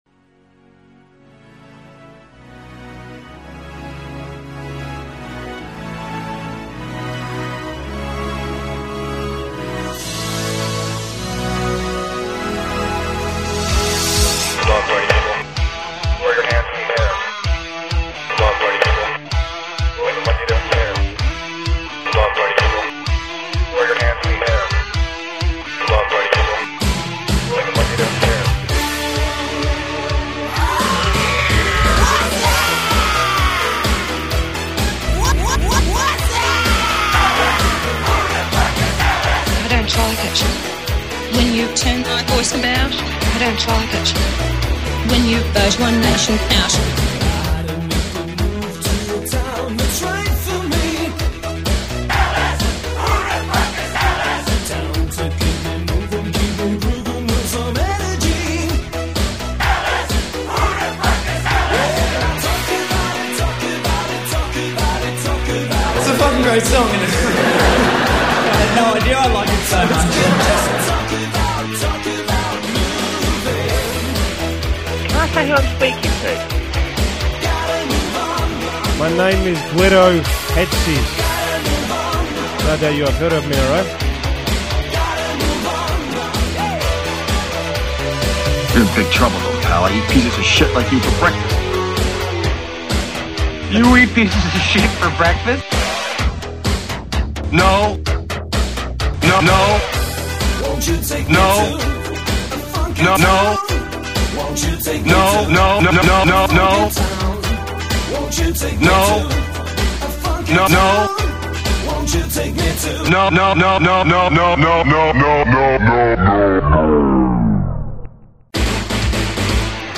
In order of appearance, this remix features the talents of: